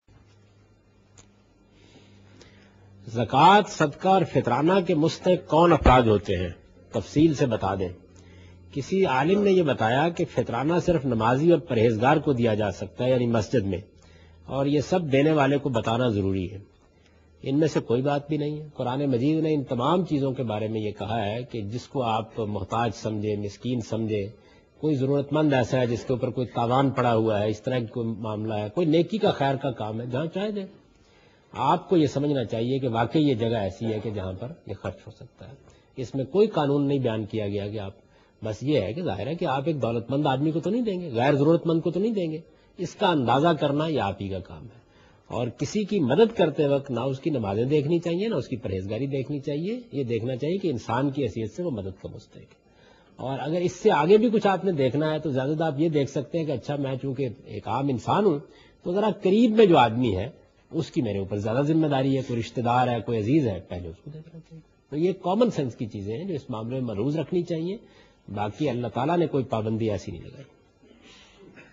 Category: Reflections / Questions_Answers /
Javed Ahmed Ghamidi answering a question regarding Fitrana.